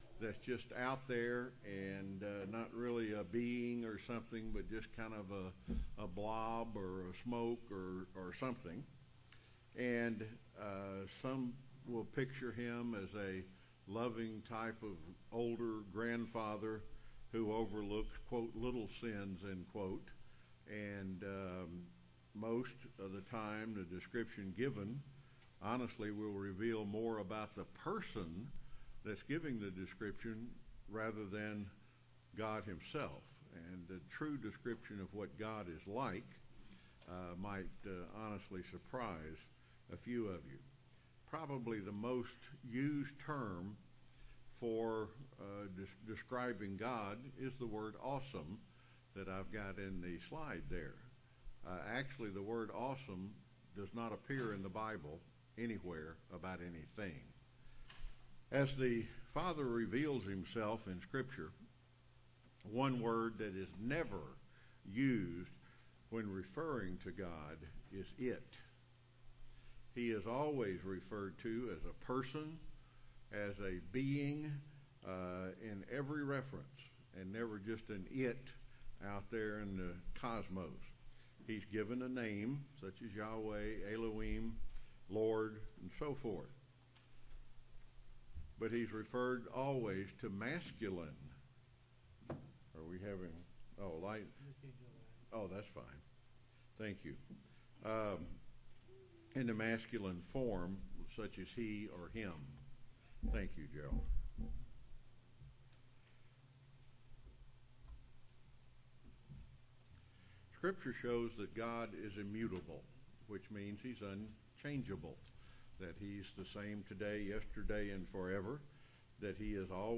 Sermon
Given in Rome, GA